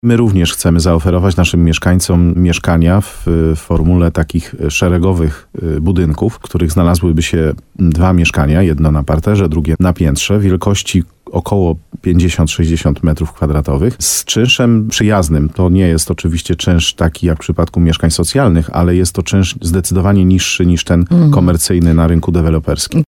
Jak mówił wójt Mariusz Tarsa w programie Słowo za Słowo na antenie RDN Nowy Sącz, w centrum Łużnej może powstać w sumie dziewięć budynków z 18 mieszkaniami.